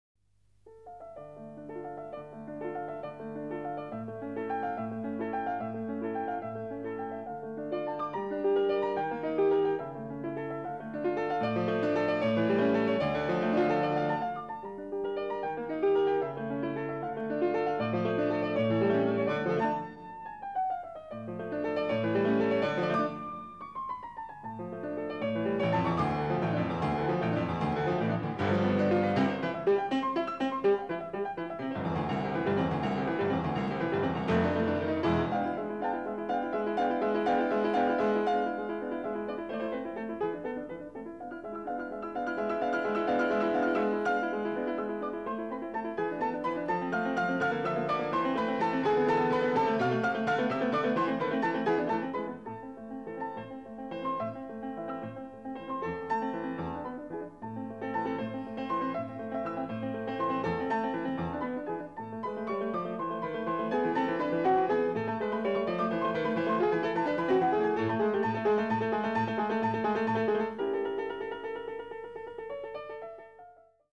Piano
Recorded: 20 April, 1971, Jerusalem, Israel